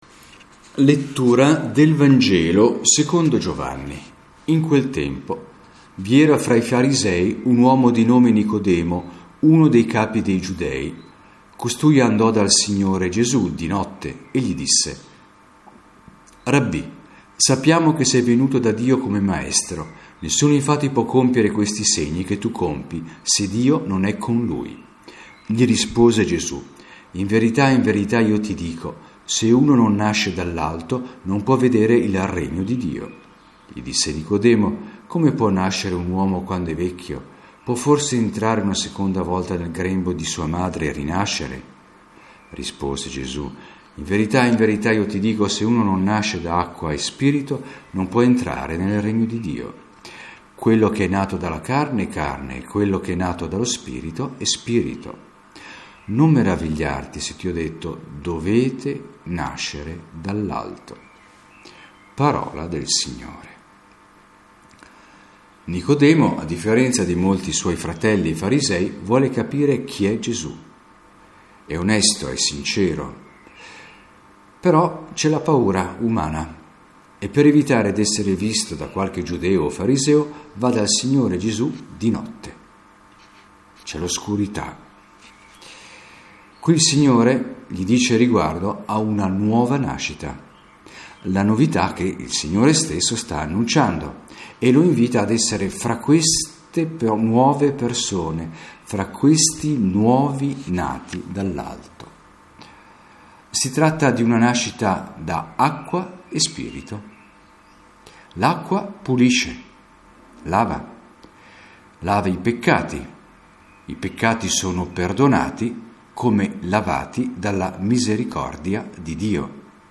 Lettura del Vangelo e commento
Audio Messa senza popolo.